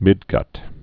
(mĭdgŭt)